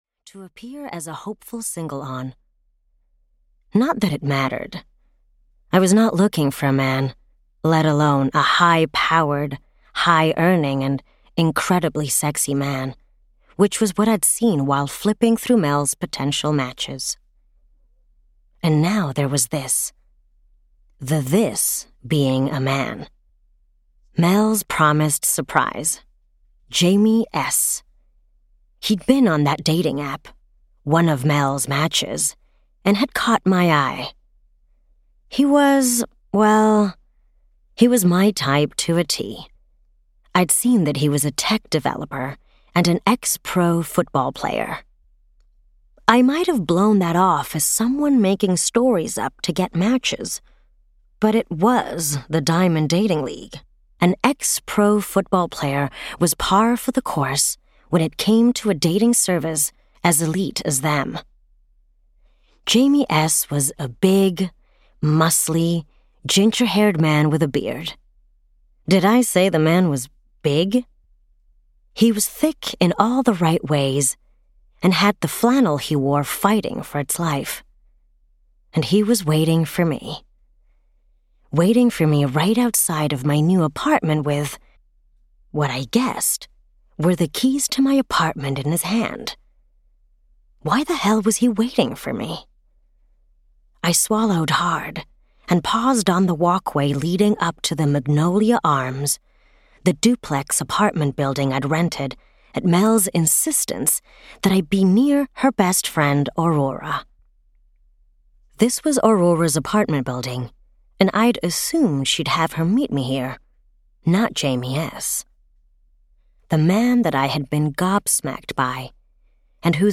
New Girl In Play (EN) audiokniha
Ukázka z knihy
new-girl-in-play-en-audiokniha